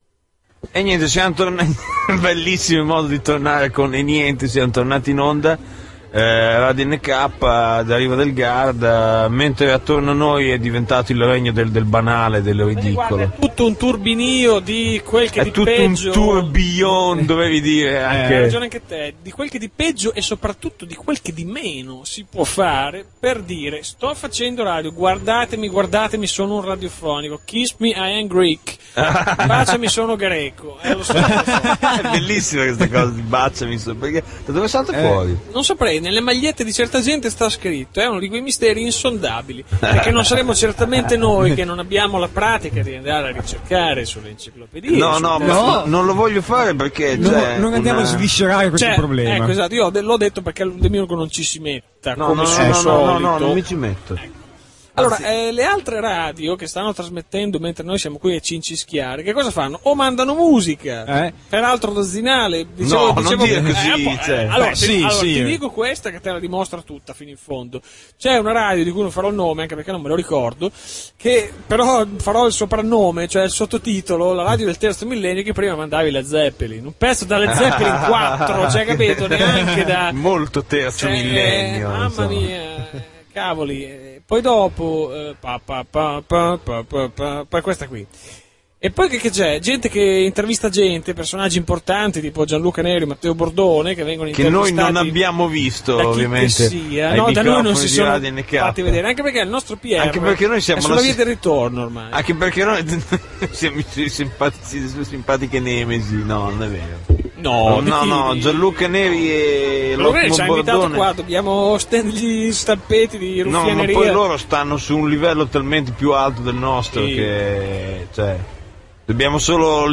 Registrata dal vivo a Radioincontri 2008 – Riva del Garda.
Attorno a noi sciamavano bambini incauti, passavano i treni di tozeur, frottavano altre web radio che con il garbo di un frotteurista in un collegio femminile.